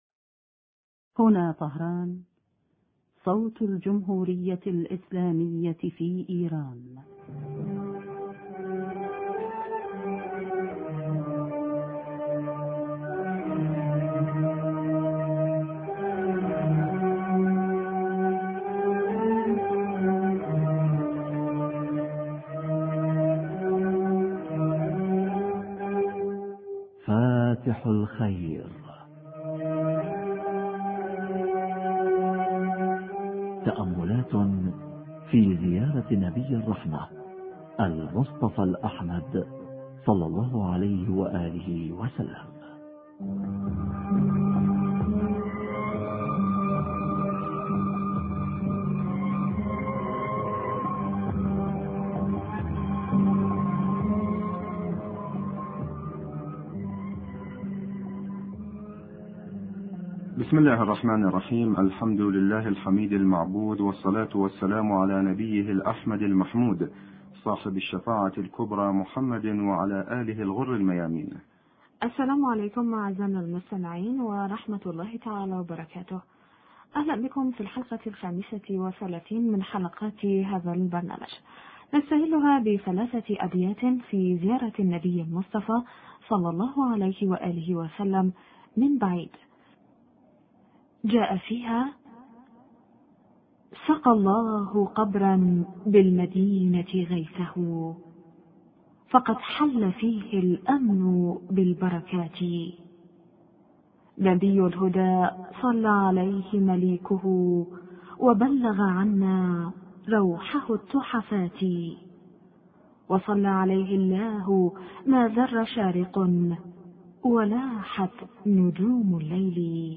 أبيات في زيارة النبي(ص) من بعيد شرح فقرة: منقذ العباد من الهلكة بأذنك... حوار